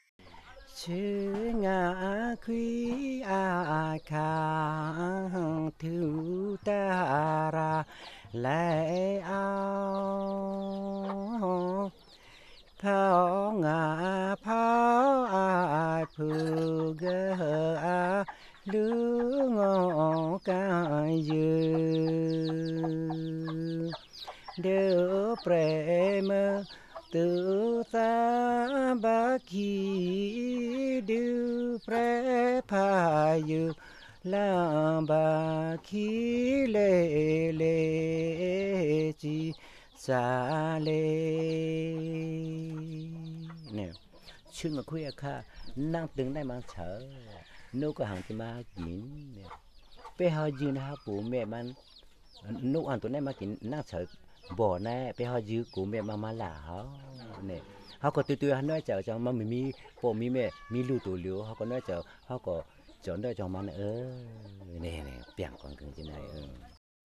unaccompanied song (male singer) an example of the traditional style of singing; the song is that of a wife who complains that her husband crows like a cock but leaves her to do all the work 1MB
Track 39 Padong song (male singer).mp3